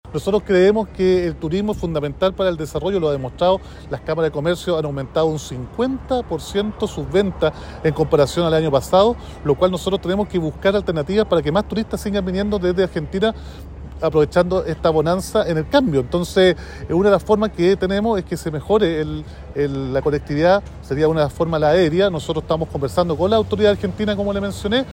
El alcalde de Temuco, Roberto Neira, reconoció que existen conversaciones con el Gobierno argentino.